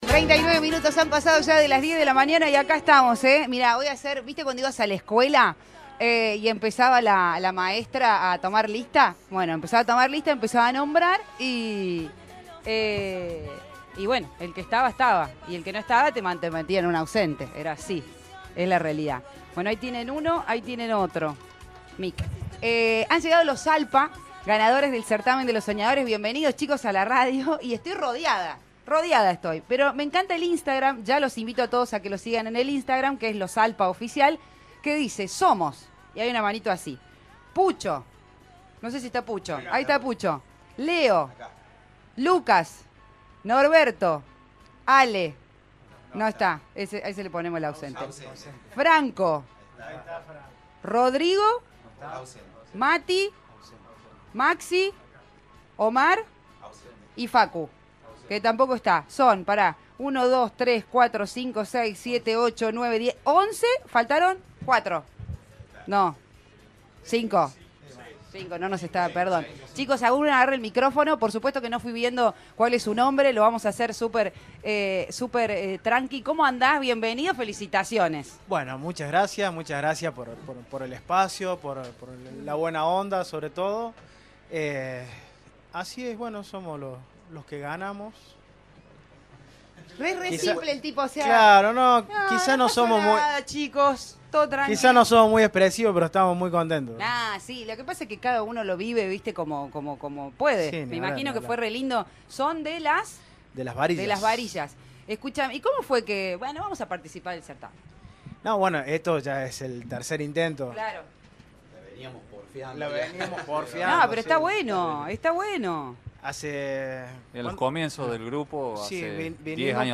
Los integrantes de Los Alpa visitaron recientemente nuestros estudios para compartir sus experiencias, proyectos y regalarnos un adelanto de su música en vivo. Durante la entrevista, ofrecieron una visión cautivadora sobre sus comienzos en el mundo de la música y sus aspiraciones para el año 2024.